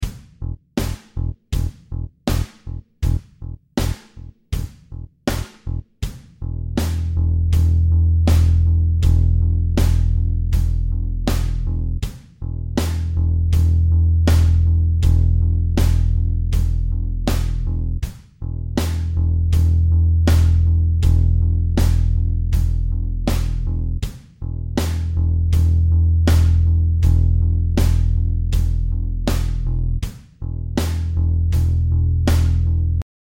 Give it a whirl yourselves - here is the droning E backing to try out each of the modes to get your ear used to their flavours:
E Drone Backing Track